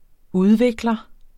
Udtale [ ˈuðˌveglʌ ]